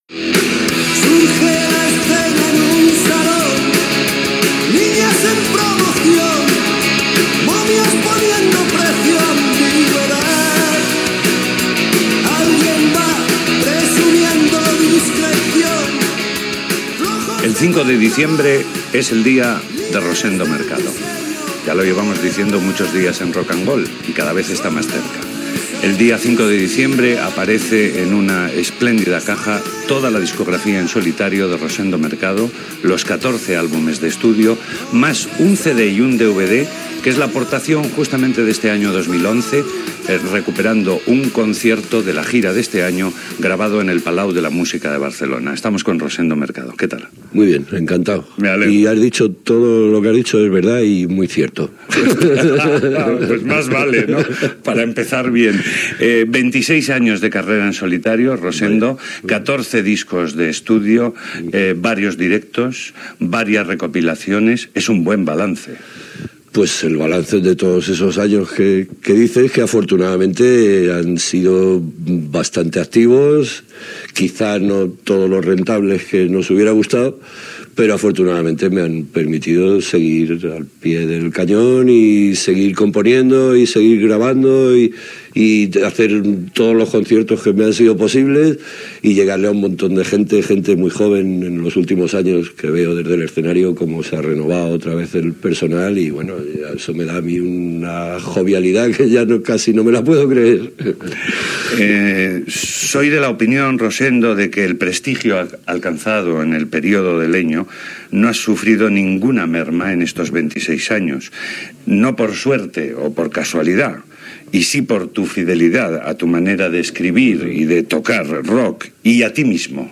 Entrevista al cantant Rosendo Mercado que està a punt de presentar tota la seva discografia en solitari
Musical